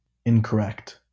• ÄäntäminenSouthern England:
• IPA: /ˌɪn.kəˈɹɛkt/